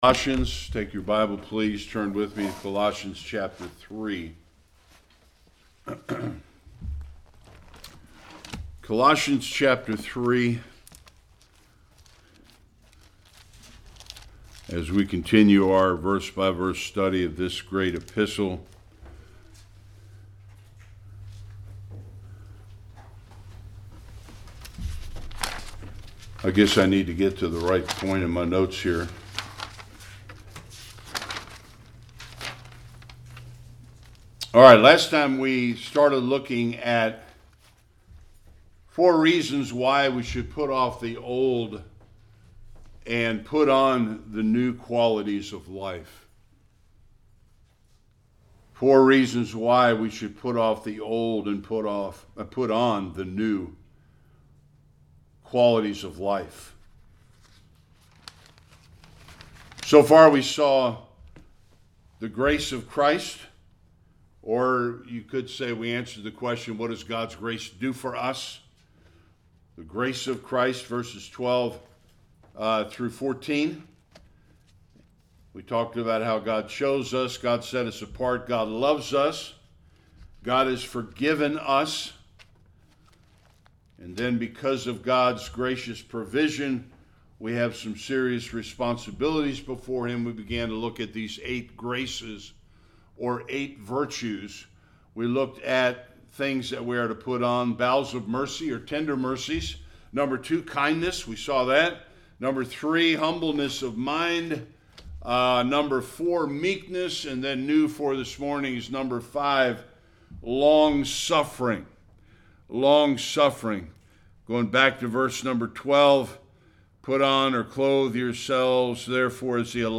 15-17 Service Type: Sunday Worship The last 4 of 8 graces or virtues Christians must put on.